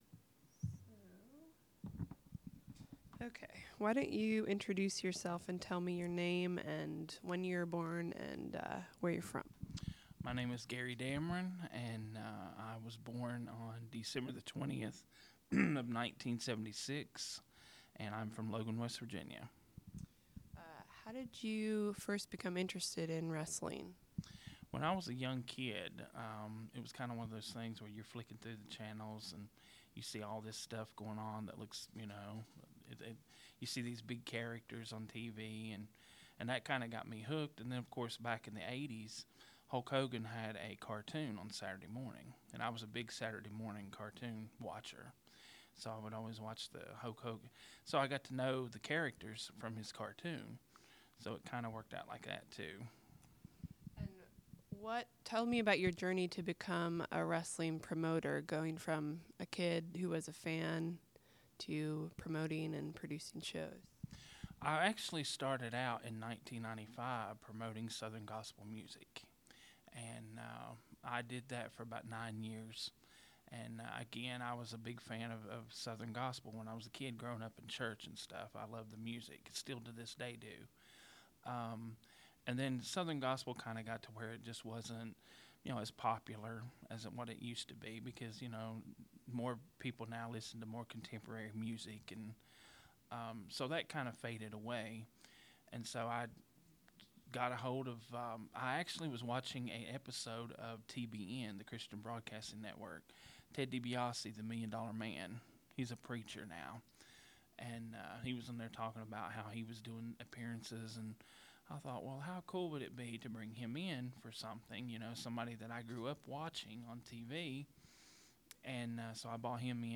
This interview is part of a series of interviews with independent professional wrestlers in West Virginia.